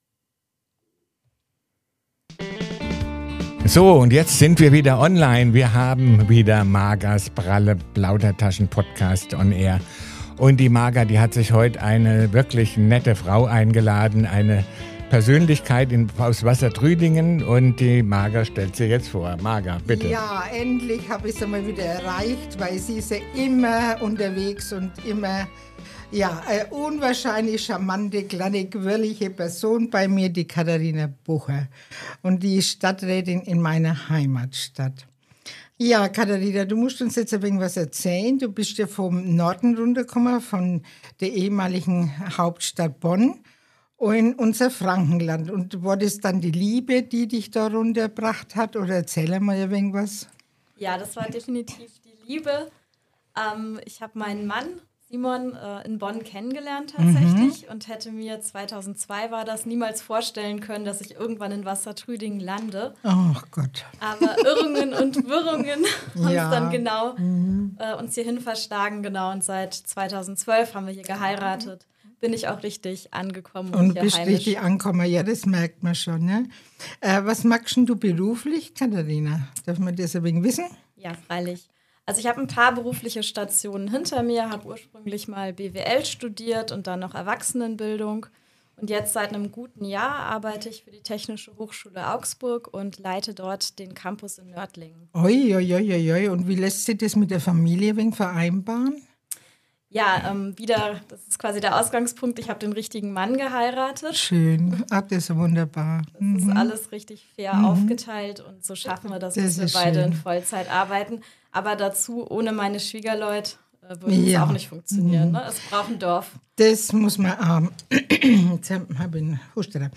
In dieser Folge spreche ich mit Katharina Bucher, einer Stadträtin, die aus Liebe von Bonn nach Wassertrüdingen gezogen ist und seitdem frischen Wind in die lokale Politik bringt.